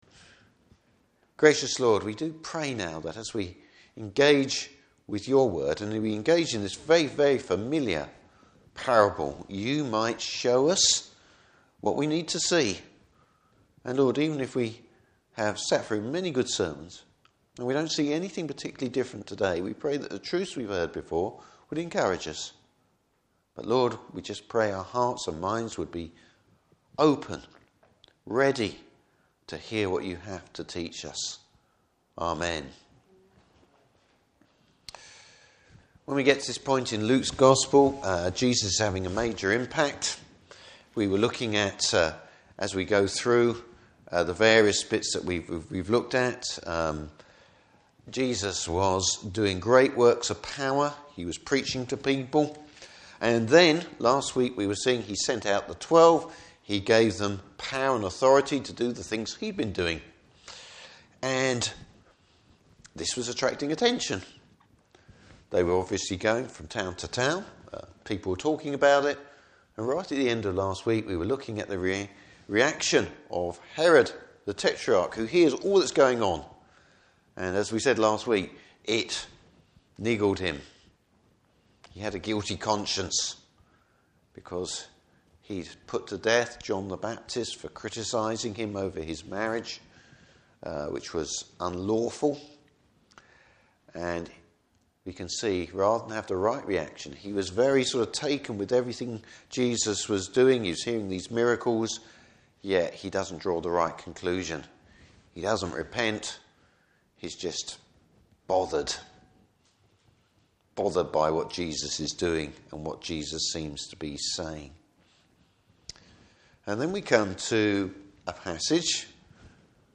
Service Type: Morning Service Bible Text: Luke 9:10-17.